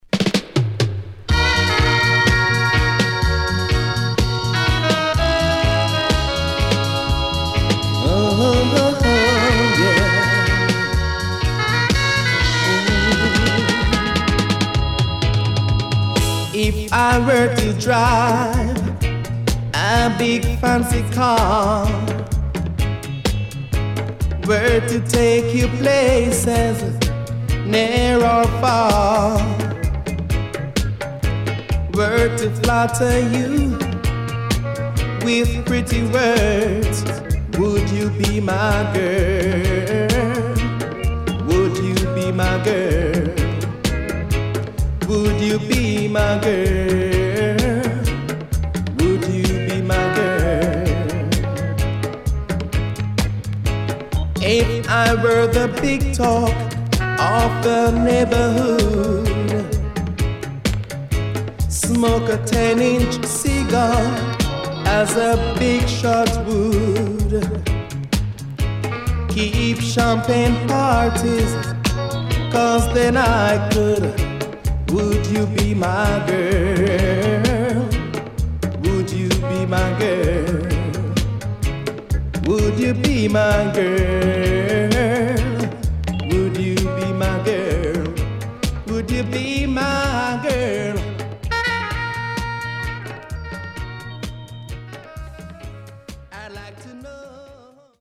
83年マイナー調からGood Mediumまで良曲多数